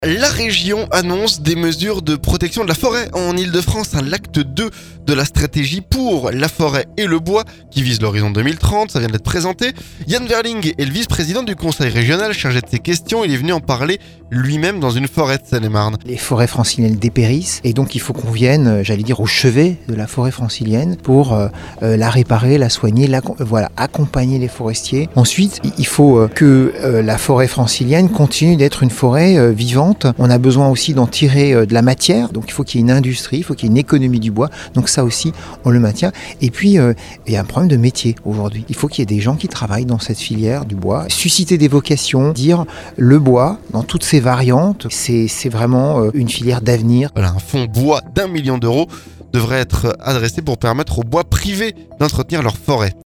Yann Wherling, vice-président du conseil régional pour la transition écologique, est venu en parler lui même dans une forêt de Seine-et-Marne.